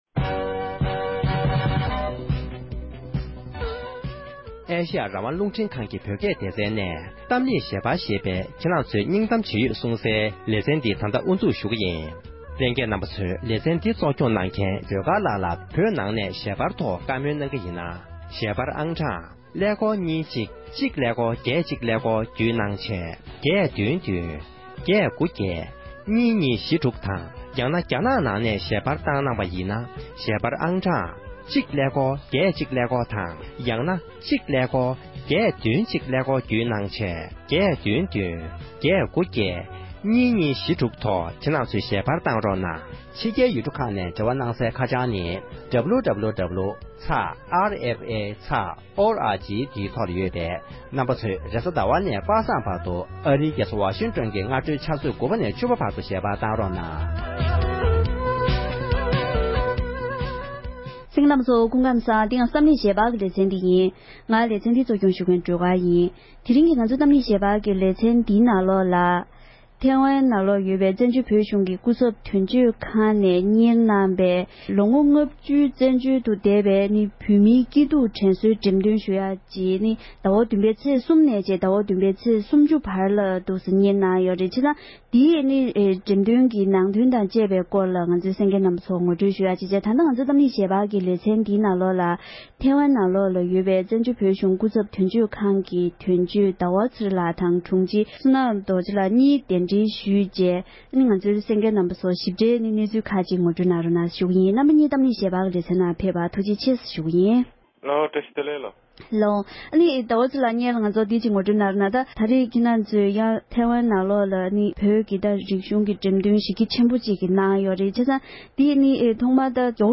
འགྲེམས་སྟོན་གྱི་ནང་དོན་སྐོར་བཀའ་འདྲི་ཞུས་པ་ཞིག་ལ་གསན་རོགས་གནོངས༎